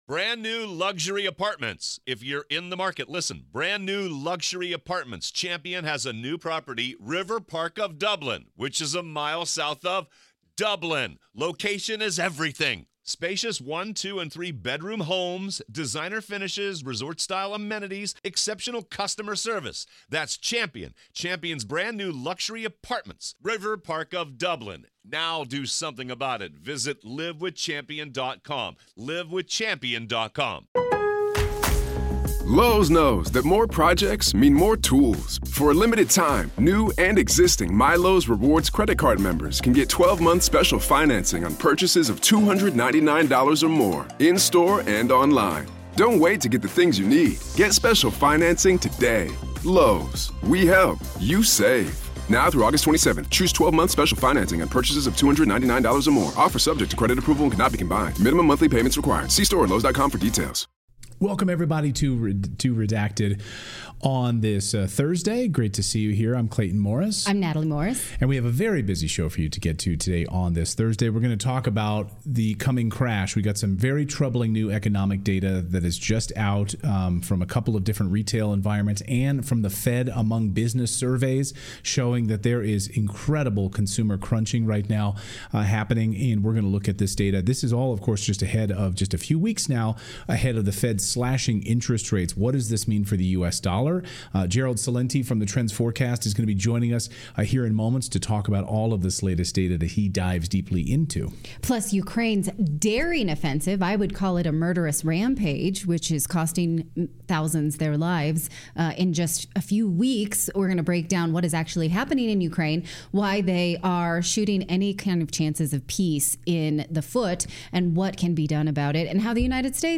Trends Forecaster Gerald Celente joins us to discuss.